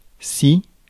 Ääntäminen
UK : IPA : /jɛs/ US : IPA : /jɛs/